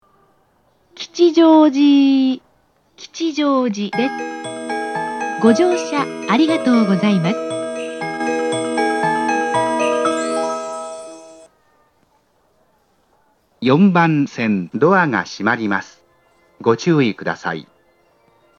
発車メロディー
余韻切りです。